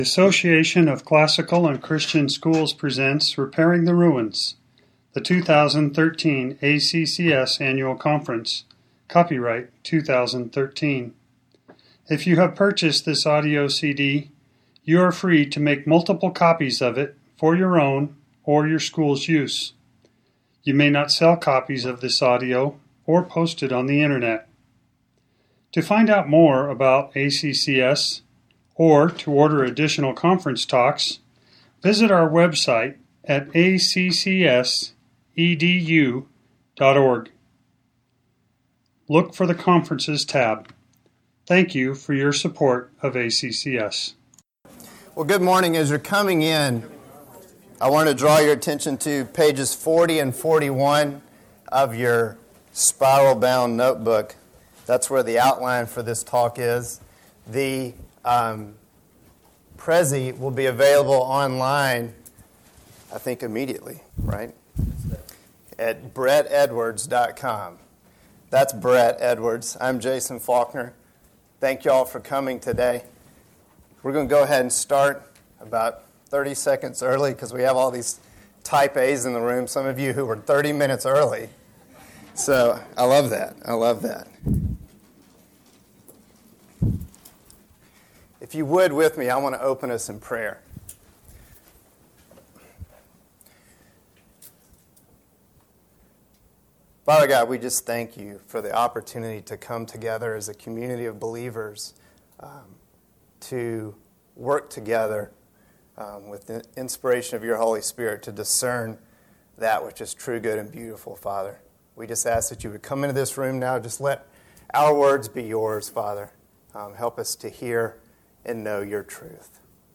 2013 Workshop Talk | 1:05:39 | All Grade Levels, Math